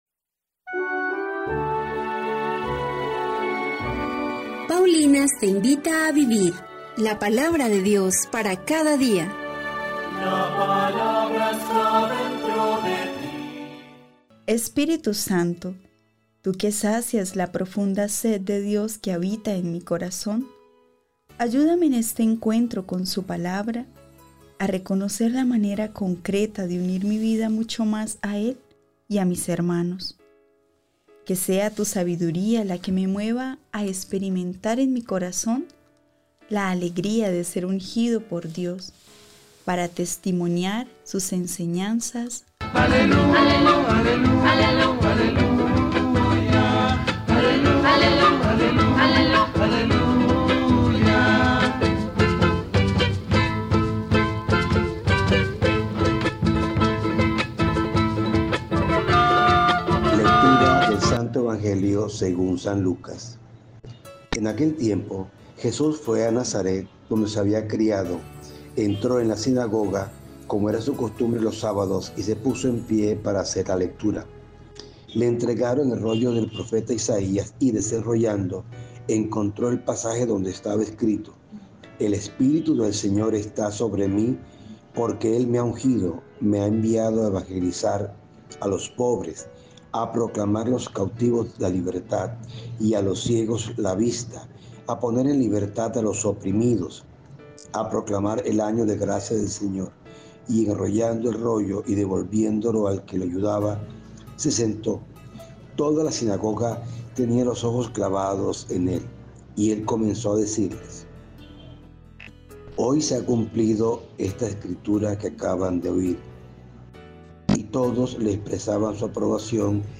Liturgia-2-de-Septiembre.mp3